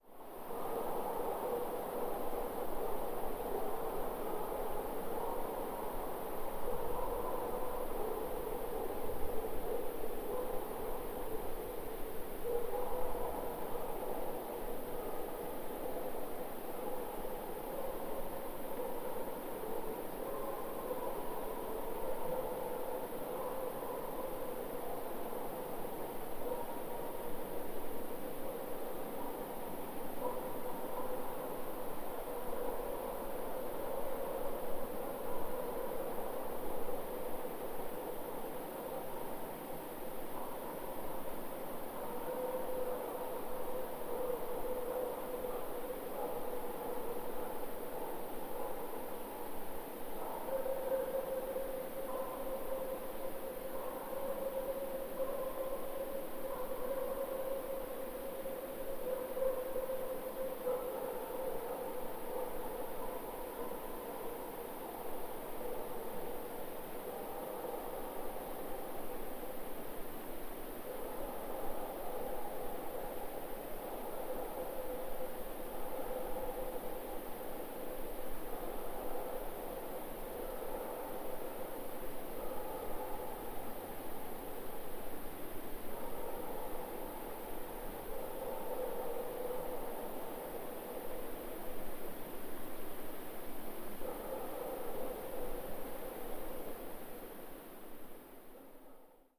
Звуки охоты, природы
Лай собаки вдалеке среди деревьев на охоте